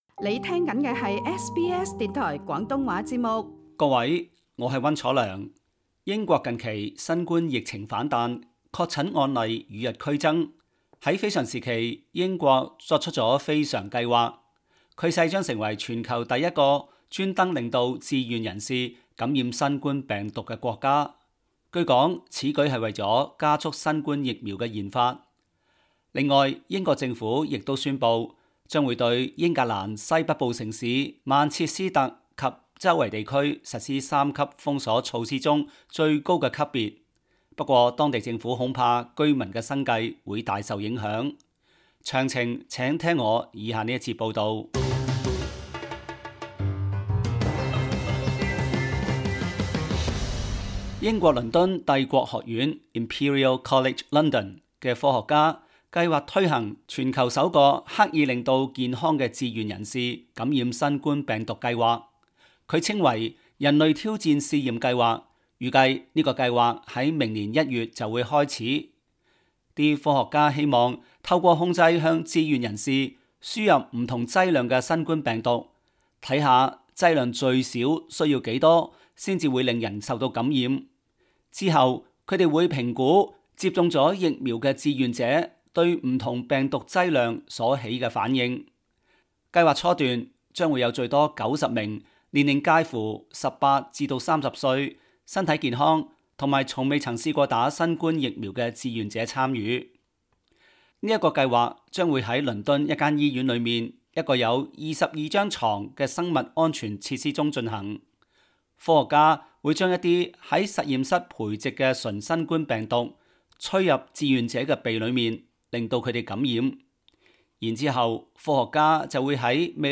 Source: AAP SBS广东话播客 View Podcast Series Follow and Subscribe Apple Podcasts YouTube Spotify Download (7.23MB) Download the SBS Audio app Available on iOS and Android 英国近期新冠疫情反弹，确诊案例遽增。